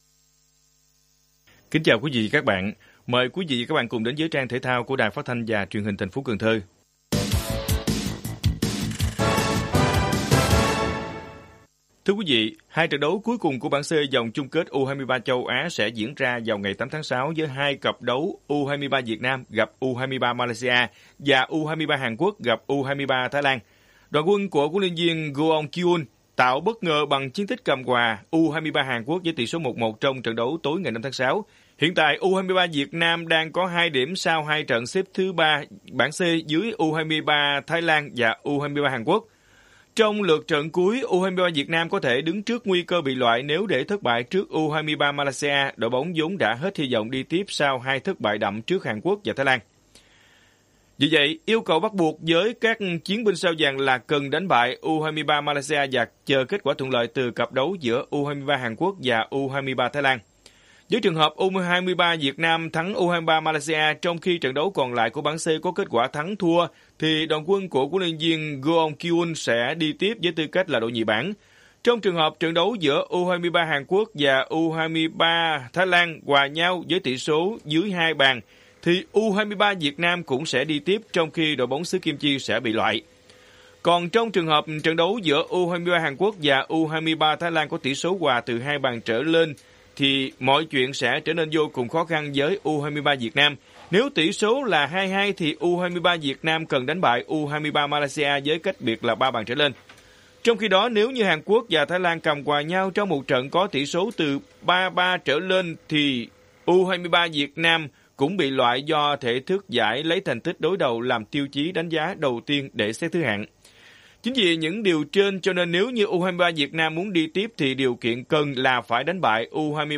RadioThể thao
Bản tin thể thao 7/6/2022